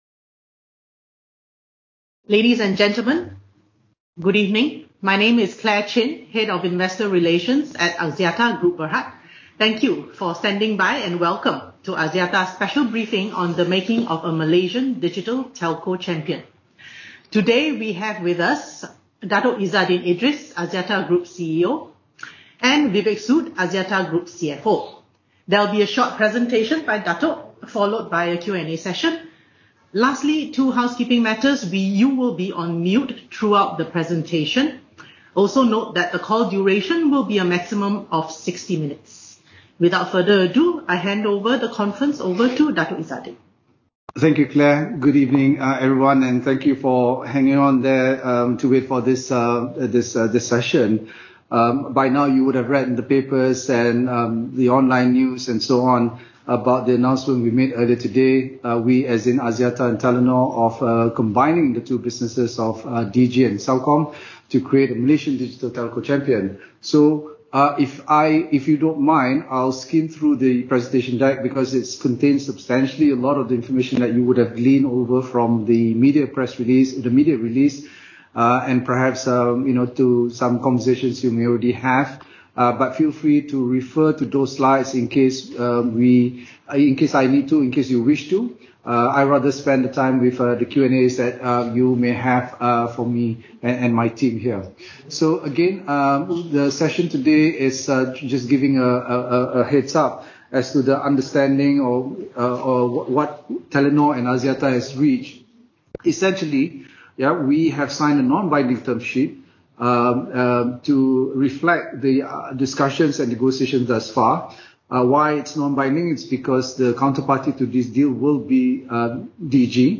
Axiata Analyst _ Investor Conference Call (Audio).m4a